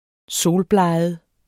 Udtale [ -ˌblɑjˀəð ]